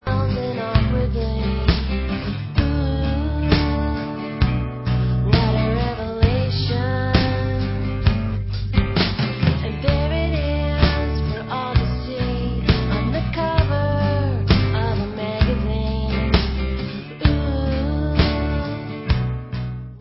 sledovat novinky v oddělení Alternativní hudba